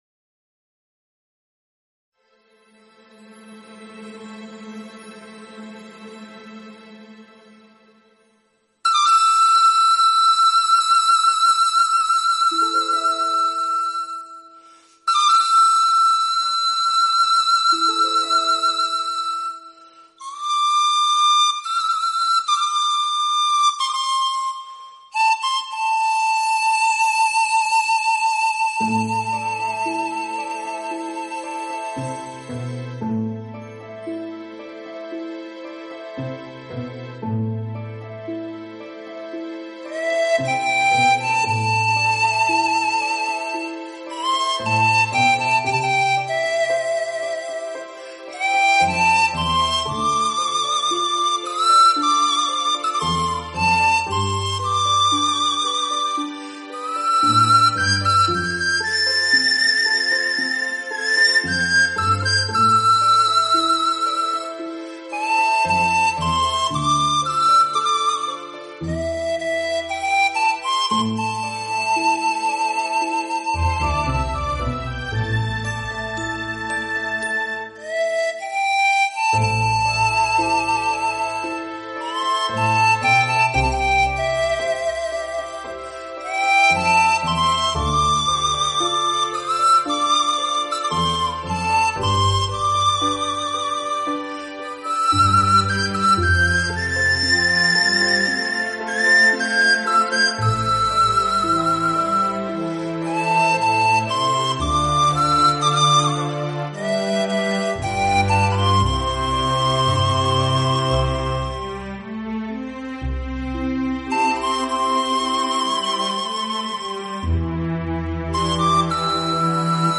排箫特有的空旷、悠远音色，加上女声、二胡、小军鼓等简炼却动人心弦的伴奏烘托，让这首乐曲演奏起来深具穿透力与感染力。